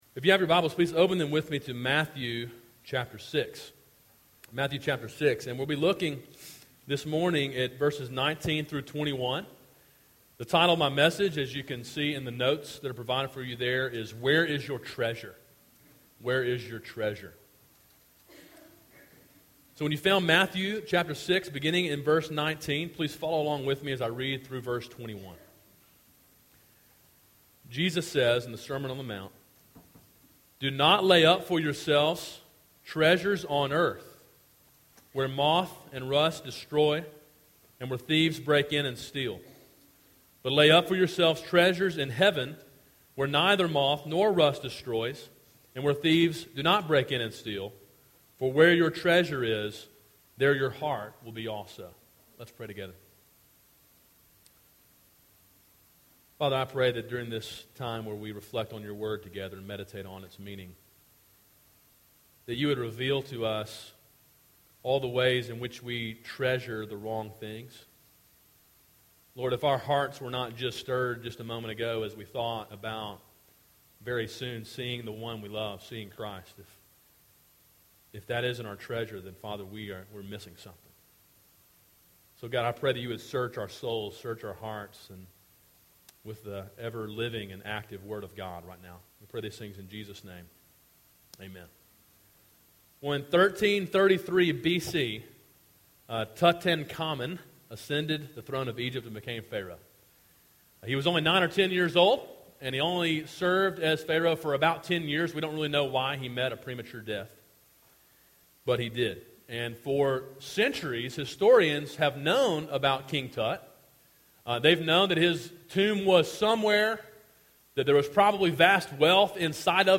A sermon in a series titled Sermon on the Mount: Gospel Obedience.